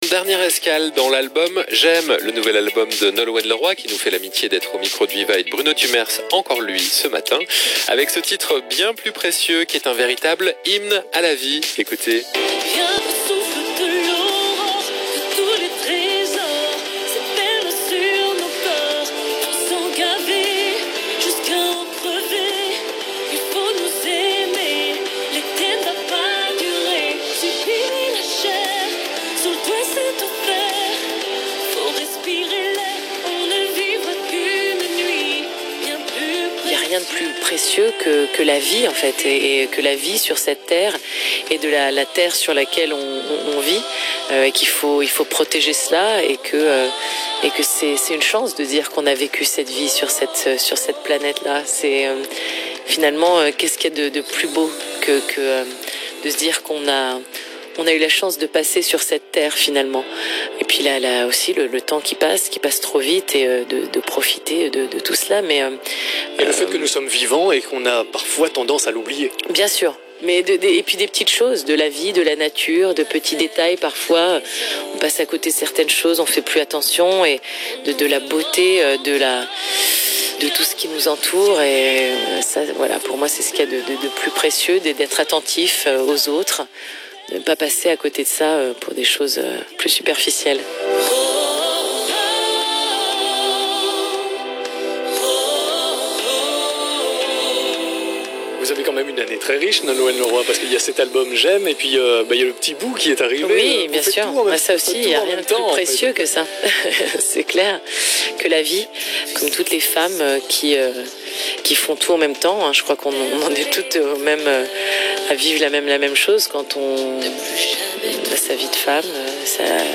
Vivacité – Nolwenn Leroy en interview dans le « Tip Top » !